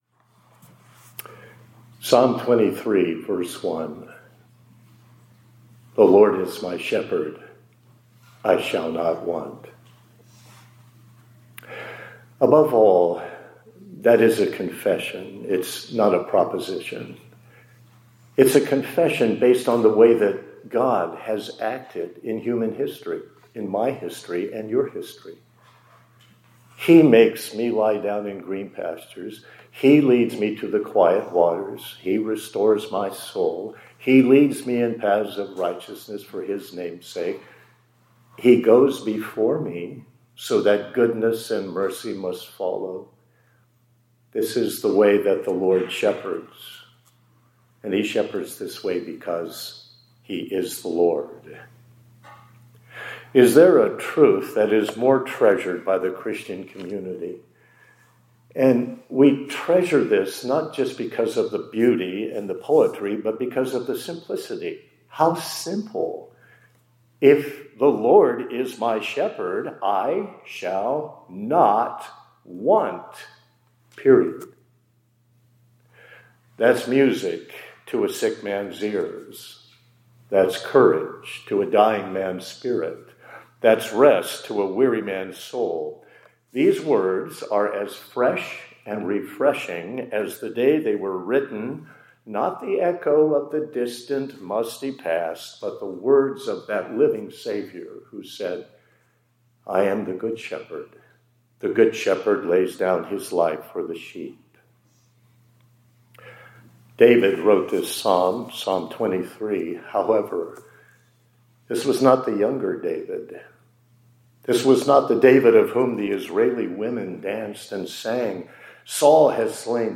2026-02-05 ILC Chapel — The Lord is my shepherd; I shall not want.